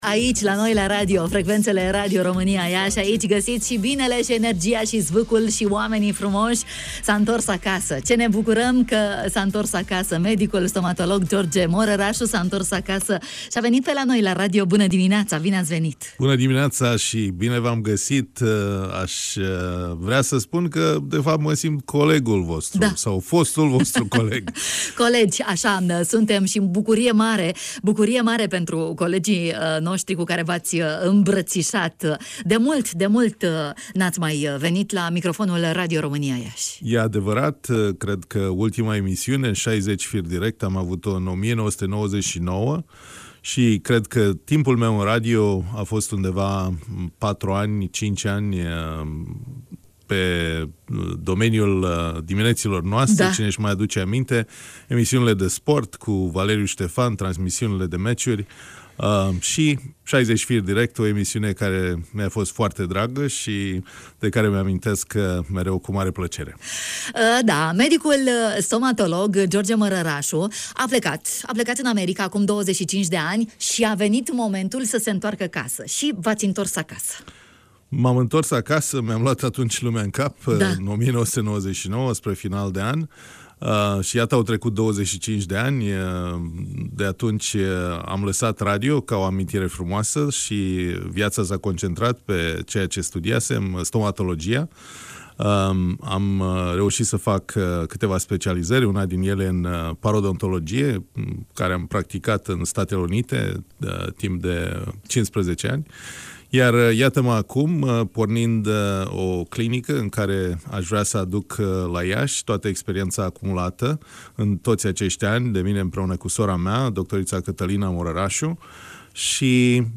Despre importanța igienei dentare, despre problemele orale care pot fi rezolvate de stomatologia modernă, s-a auzit la microfonul Radio România Iași.